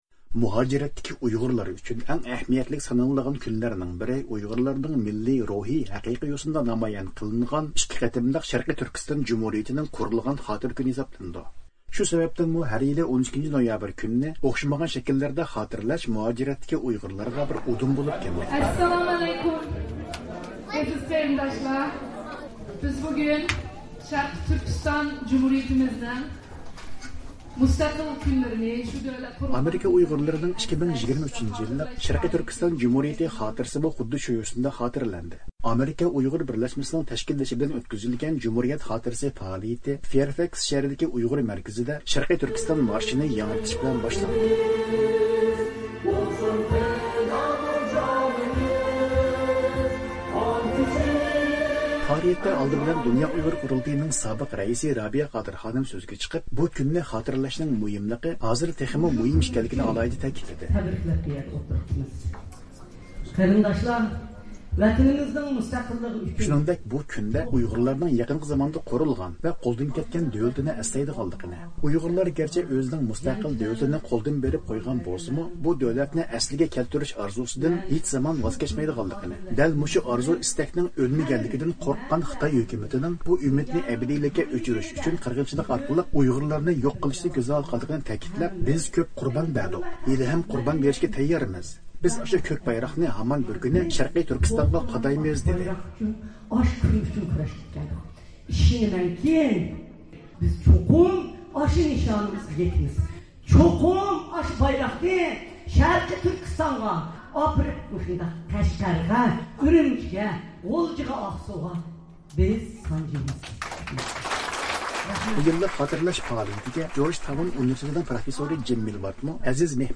ۋاشىنگتوندىن مۇخبىرىمىز